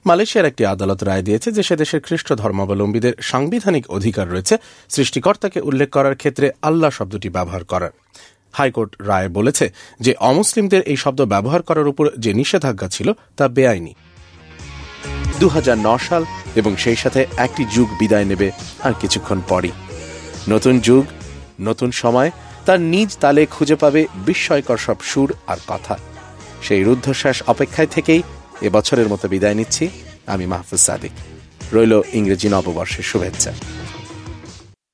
Bengali, Male, 20s-40s